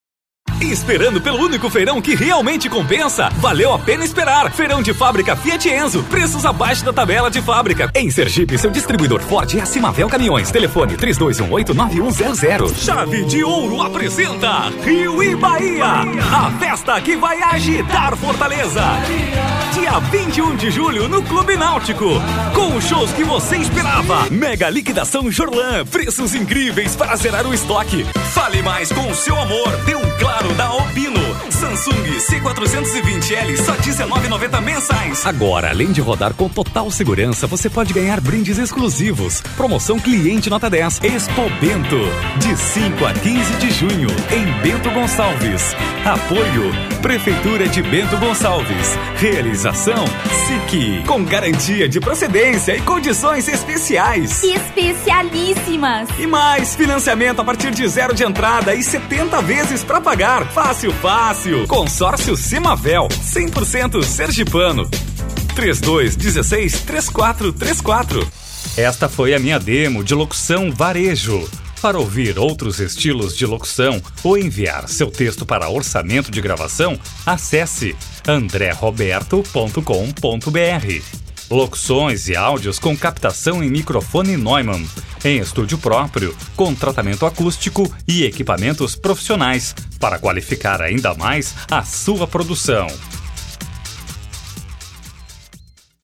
Professional announcer for more than 20 years, with his own studio and professional equipment, using microphone Neumann TLM 103, the guarantee of a great work.
Sprechprobe: Werbung (Muttersprache):